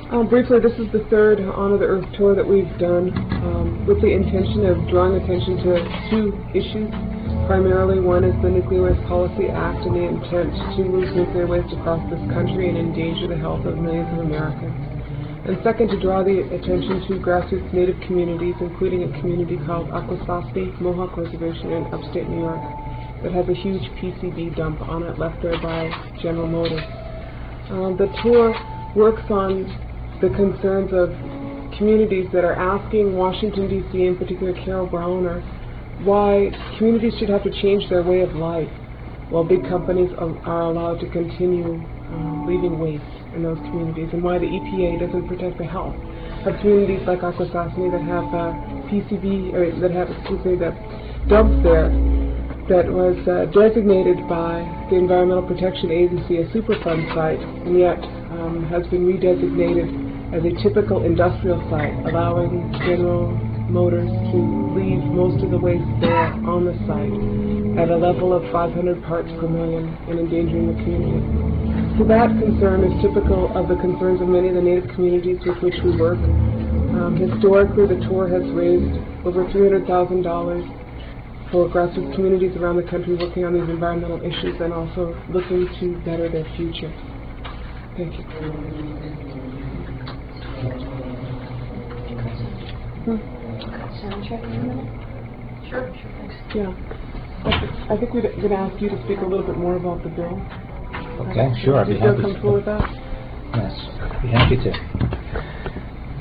lifeblood: bootlegs: 1997-09-15: honor the earth press conference - poughkeepsie, new york
02. press conference - winona laduke (1:46)